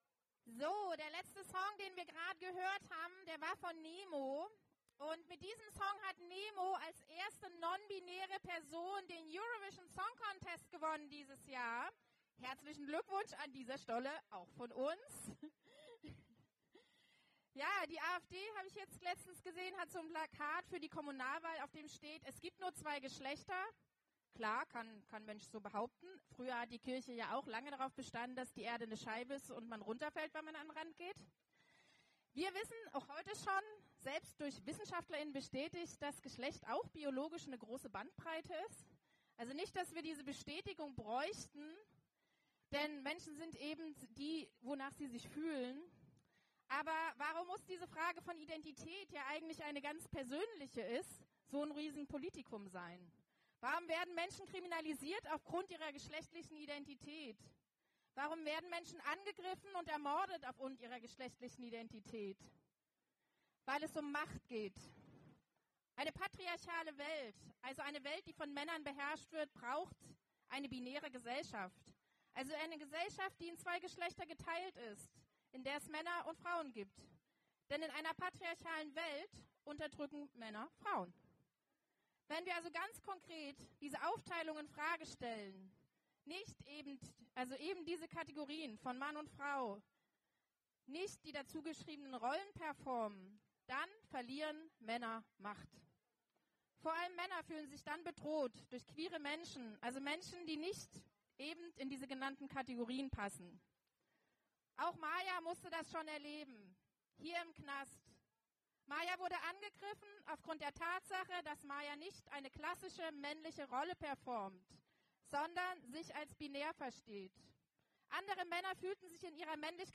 Solikundgebung in Freiburg